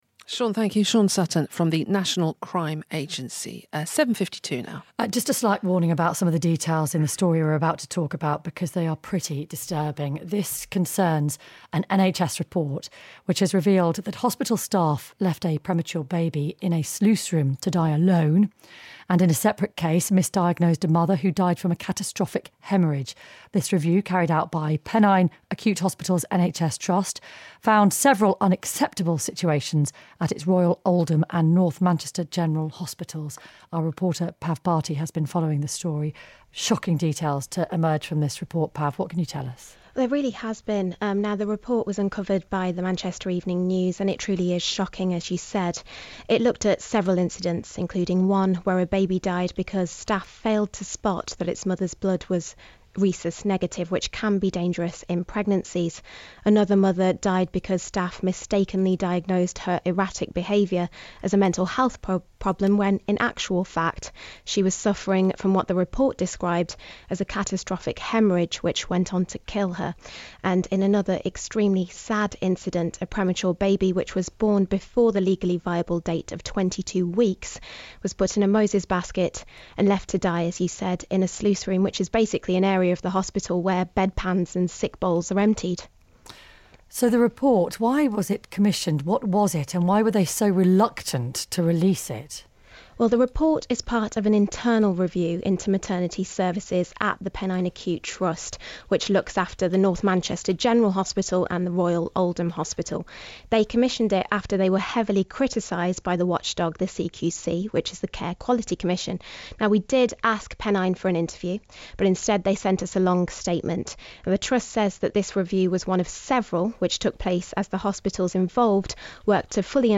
My report on BBC 5Live -The Pennine Acute Trust says it is addressing failings identified in a leaked report which led to the deaths of several mothers and babies at North Manchester General Hospital and the Royal Oldham Hospital.